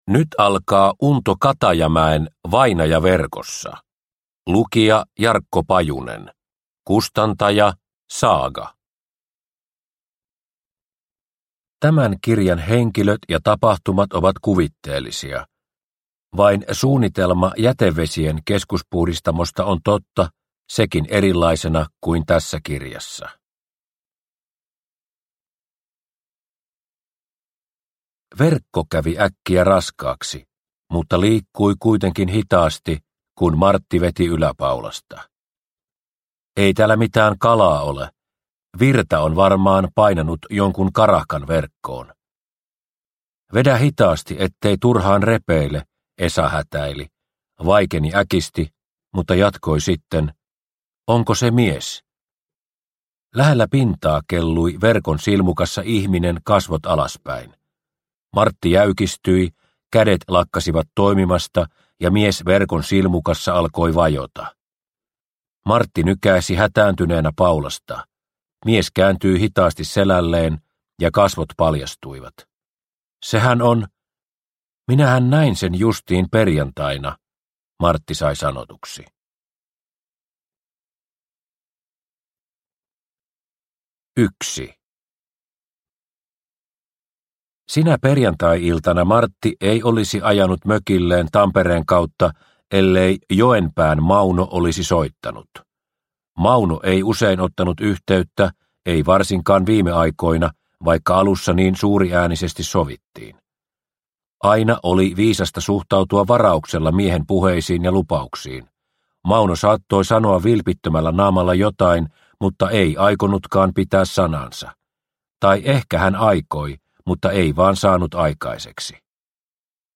Vainaja verkossa (ljudbok) av Unto Katajamäki